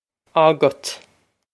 Pronunciation for how to say
a-gut
This is an approximate phonetic pronunciation of the phrase.